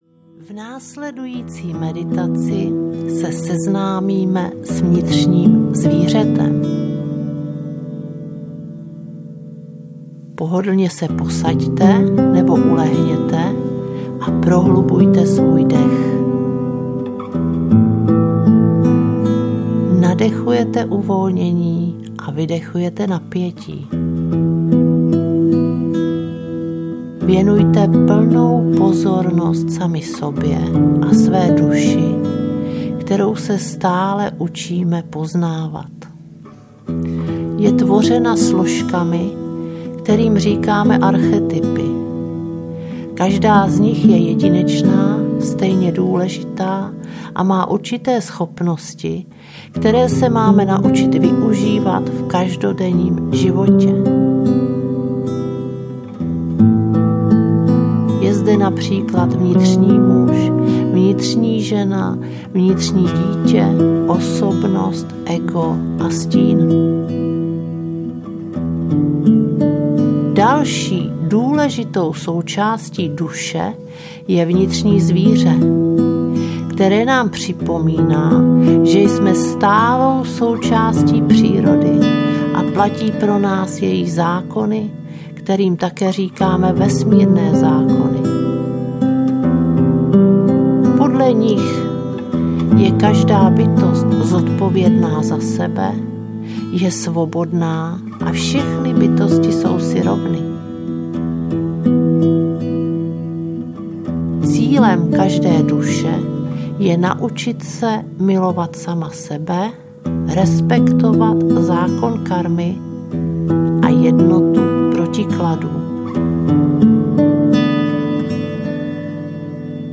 Ukázka z knihy
navrat-k-prirode-meditace-vnitrni-zvire-strom-audiokniha